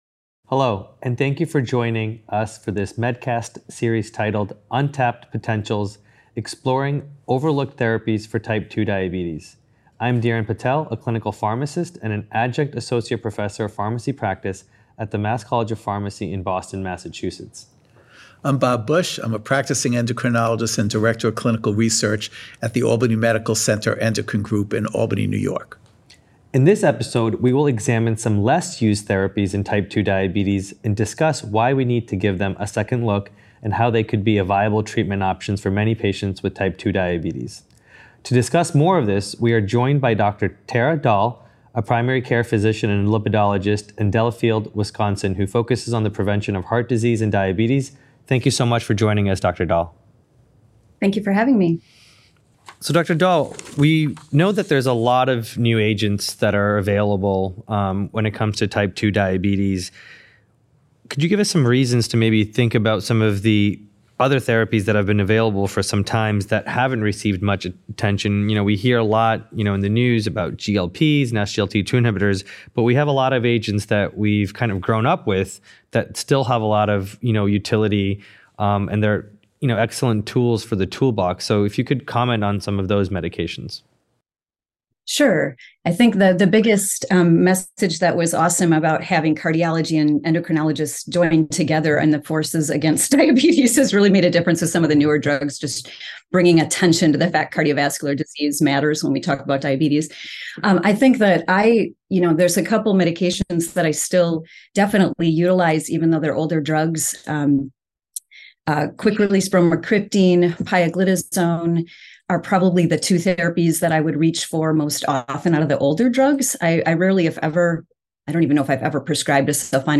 In this episode of MEDcast, the expert panelists examine some underutilized therapies in type 2 diabetes (T2D) and explore their potential as effective treatment choices for many patients.